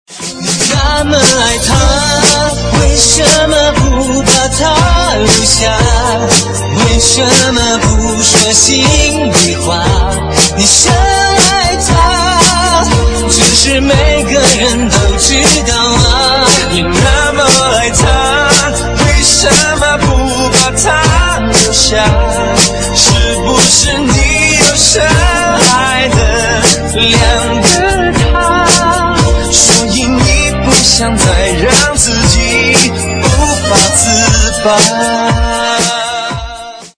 DJ铃声 大小